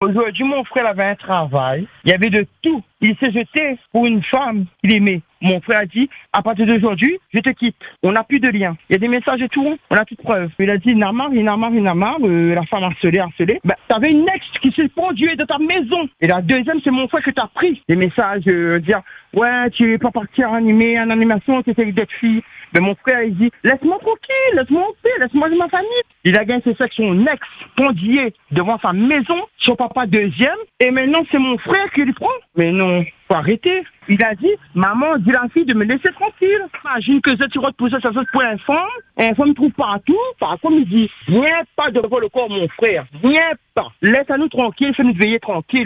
Sur l’antenne, sa sœur a accepté de témoigner, avec beaucoup de pudeur, pour exprimer la douleur de la famille et les interrogations qui demeurent.